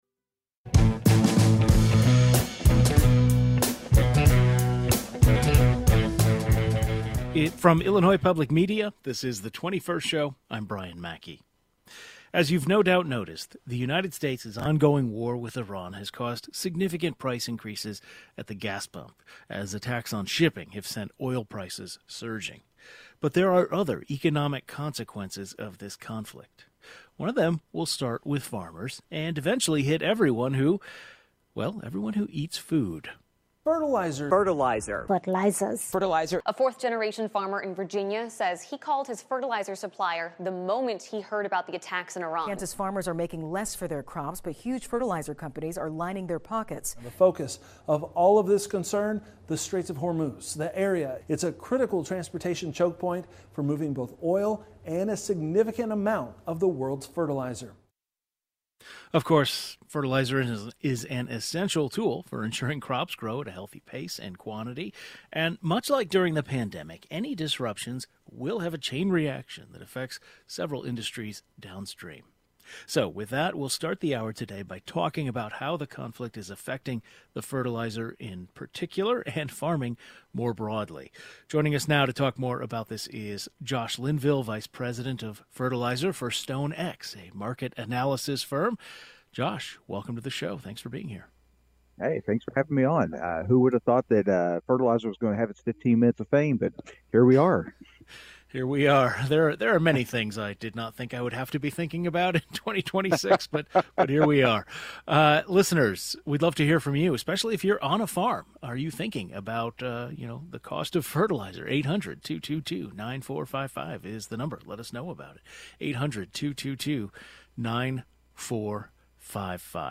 There's been a lot of focus on how the ongoing war with Iran has affected gas and oil prices, but fertilizer prices have also severely risen. A market analyst explains what this means for the agriculture industry and farmers in Illinois.